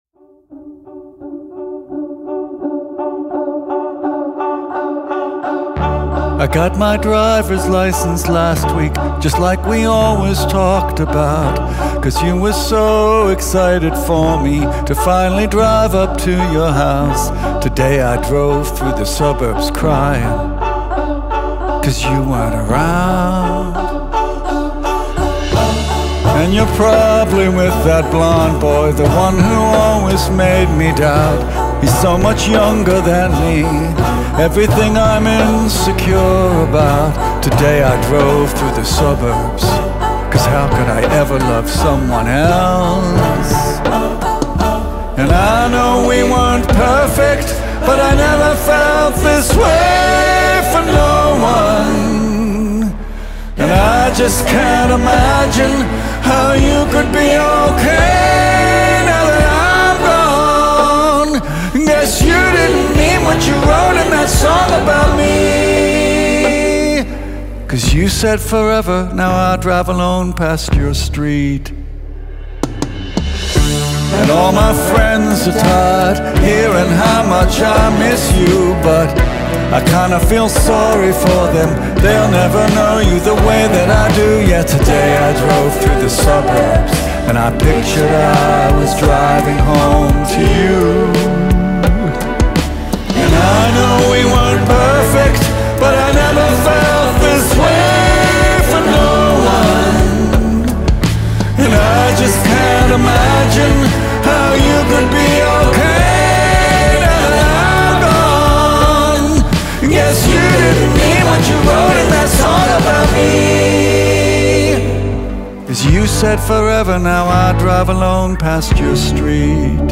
It is thoughtful without losing its sting.
Smart. Controlled. Still cutting.